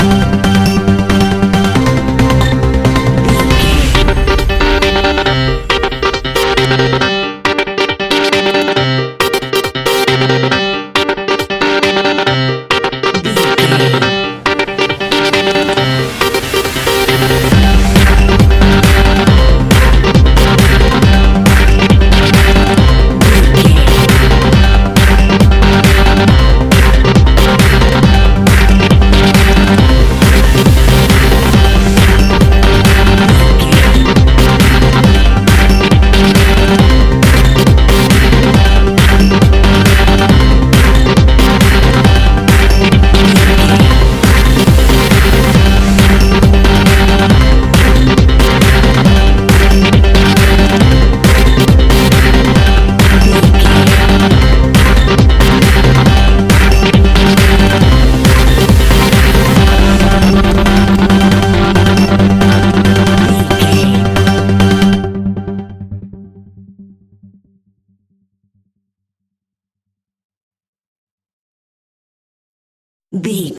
Aeolian/Minor
Fast
energetic
uplifting
hypnotic
drum machine
piano
synthesiser
uptempo
instrumentals
synth leads
synth bass